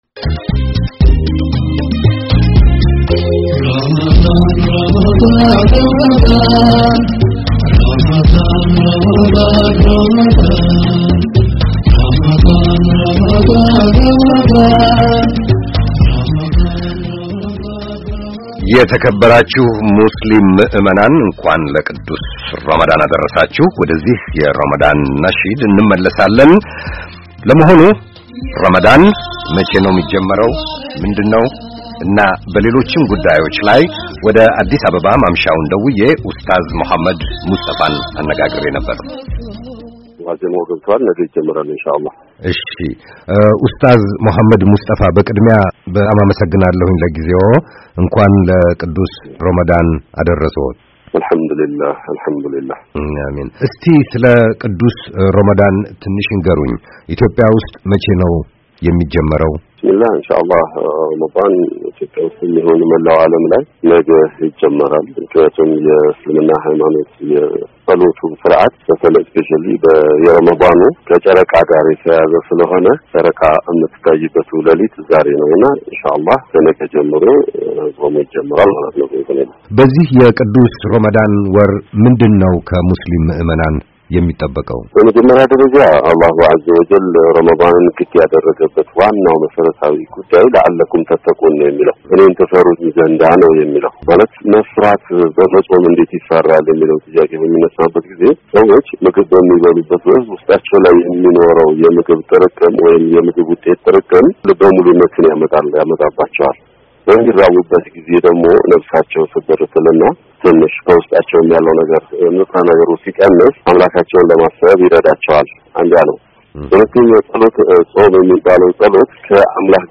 በቅዱስ ራማዳን ወር የሚከናወነው የፆም ሥርዓት አላህን ለመፍራት እንደሚያግዝ አዲስ አበባ የሚገኙ የእሥልምና መምህር በቪኦኤ ባስተላለፉት መልዕክት ተናግረዋል።